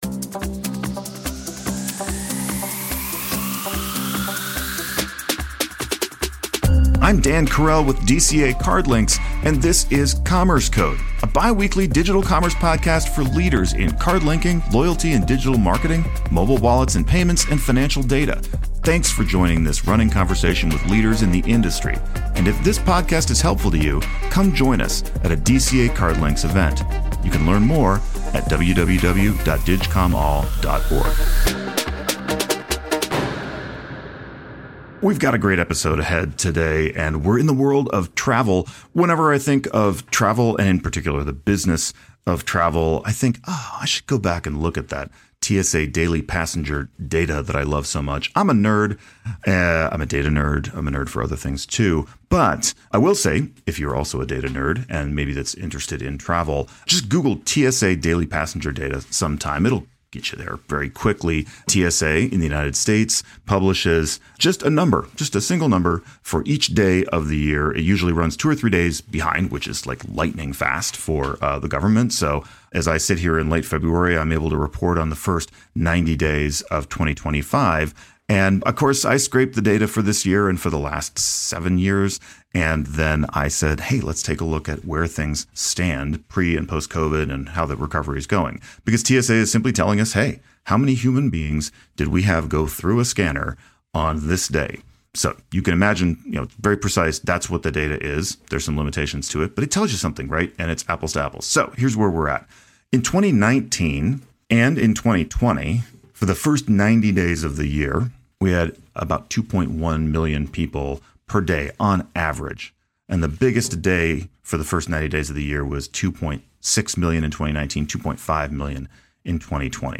Stay connected and get the latest insights, analysis and news from the leading fin-tech companies, merchants and payments including Microsoft, Mastercard, Samsung, LVMH, Hilton and more. The podcast features insights and interviews from Digital Commerce Alliance (formerly CardLinx) members around the world.